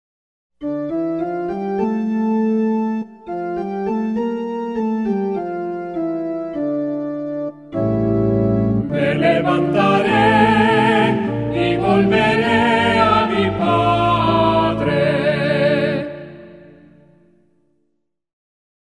SALMO RESPONSORIAL Del salmo 50 R. Me levantaré y volveré a mi padre.